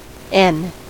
Summary Description En-us-n.ogg Audio pronunciation of the term 'n' in United States English.
En-us-n.ogg